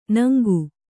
♪ naŋgu